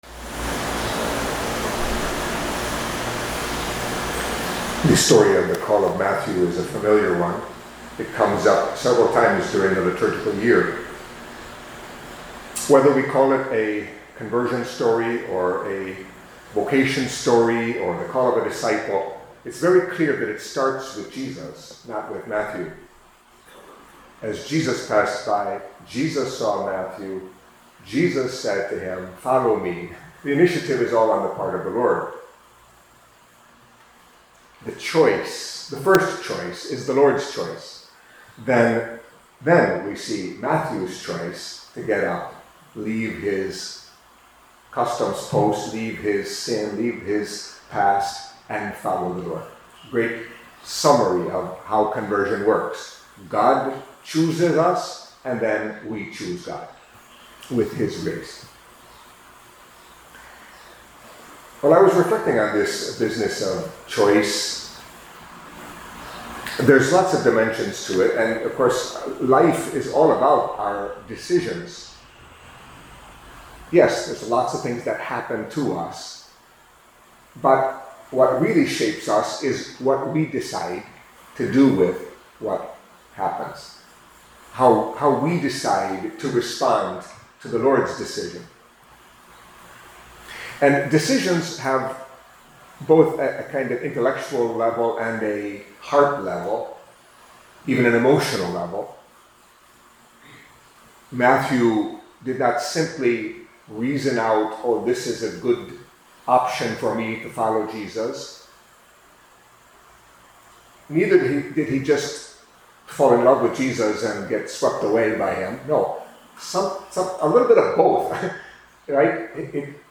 Catholic Mass homily for Friday of the Thirteenth Week in Ordinary Time